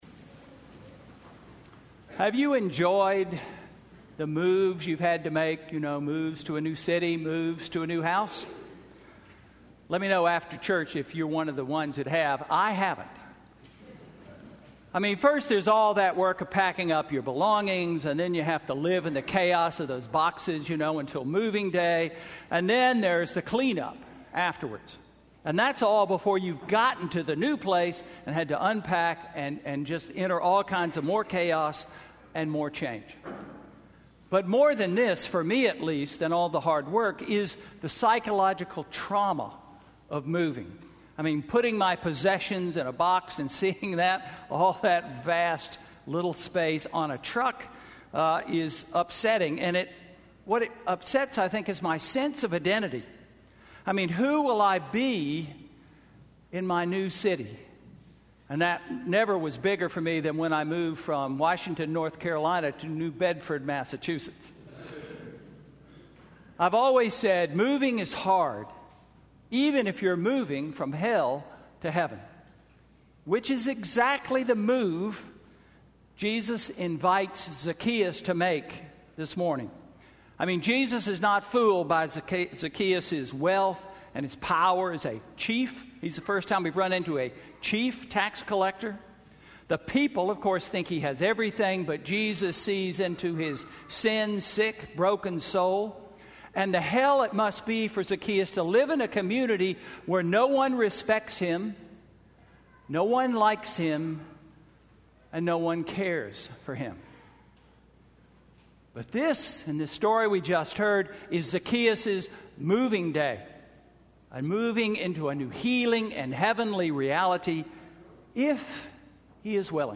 Sermon–October 30, 2016